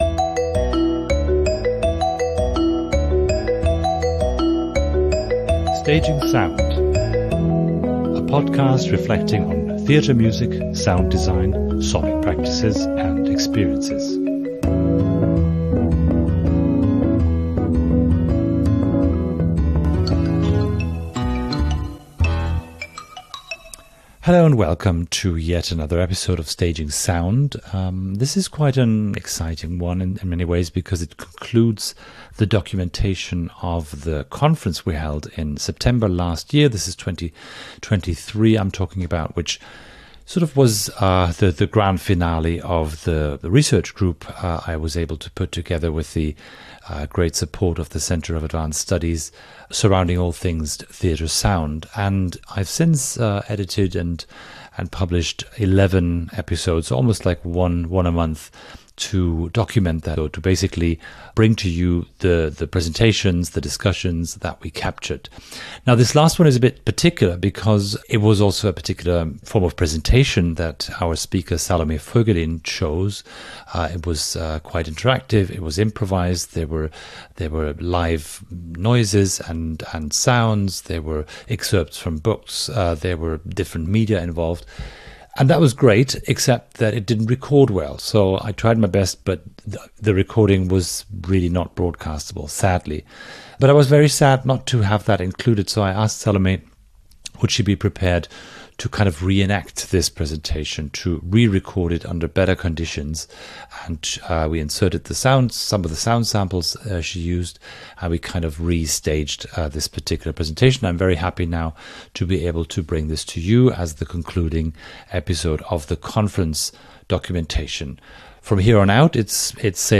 Reflecting theatre music and sound design - Conference documentation: Theatre Sound as Collaboration.